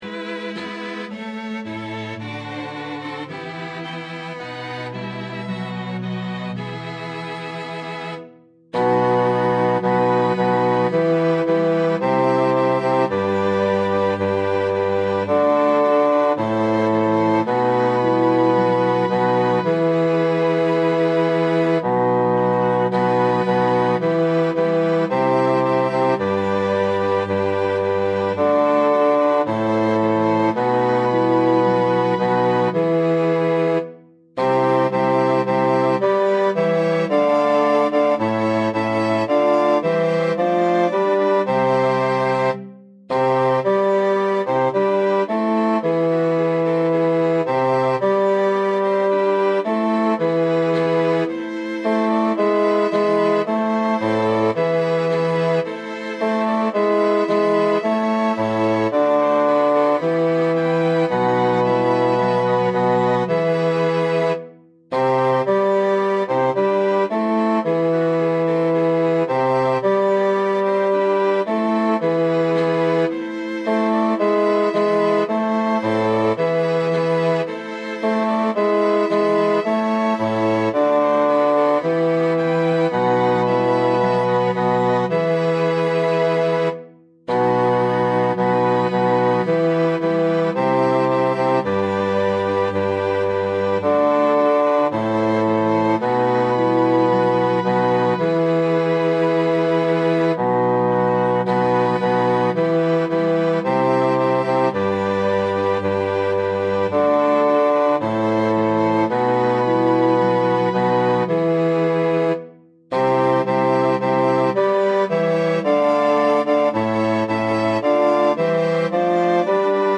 maria-wallt-zum-heiligtum-eccard-nach-grote024-einstudierung-bass.mp3